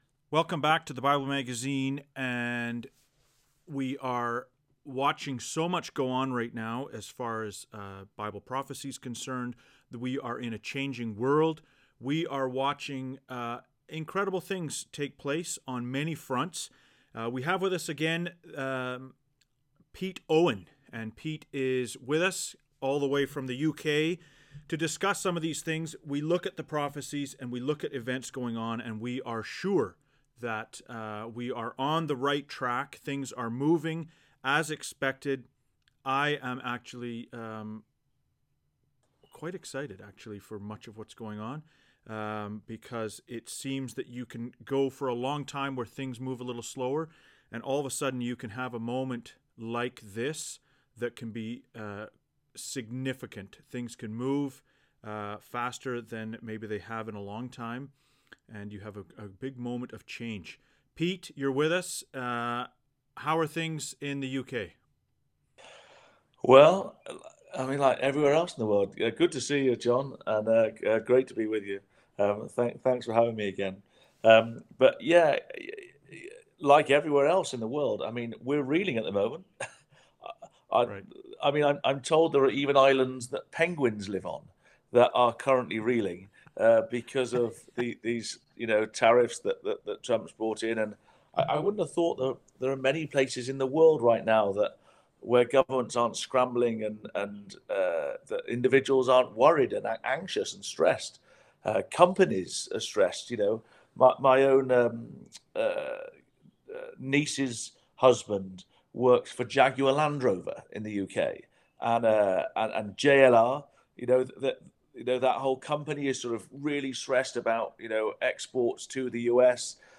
The video of this conversation has been posted to YouTube, available below.